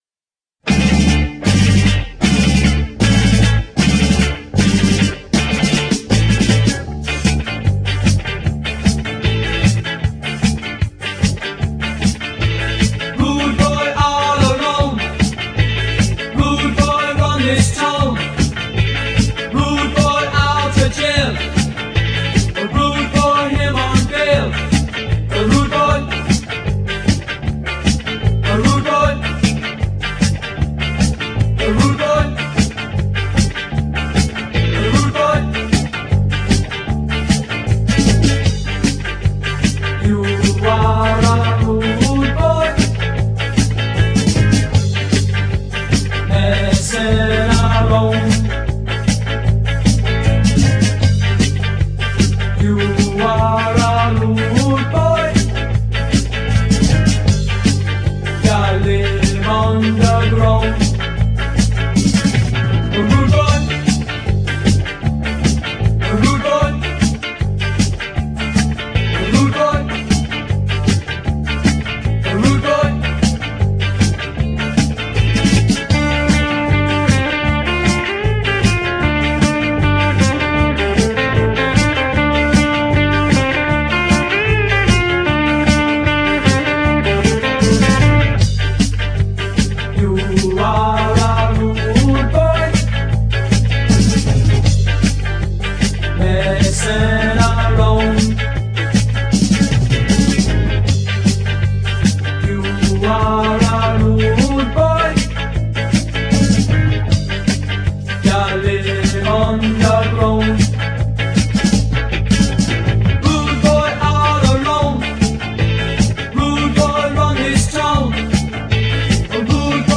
パンク、パワーポップ、スカなどの幅広いジャンル で飽きのこない内容。